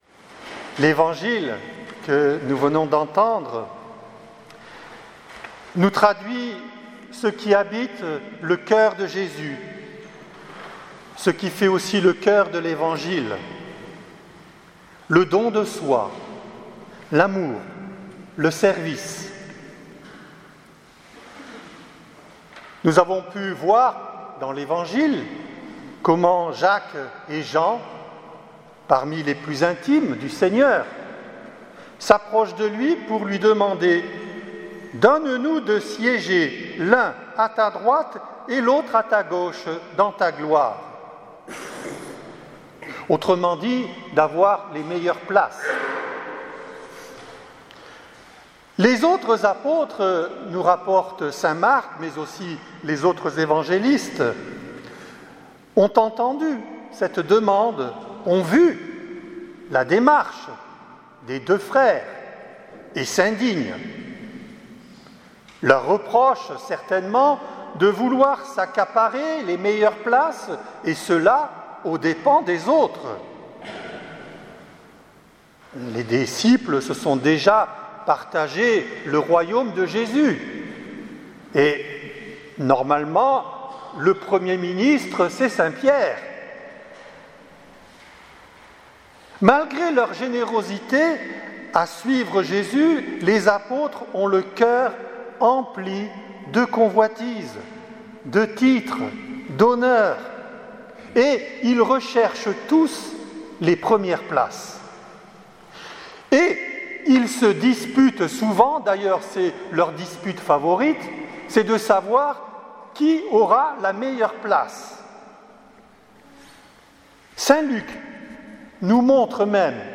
Homélie du 29ème dimanche du Temps Ordinaire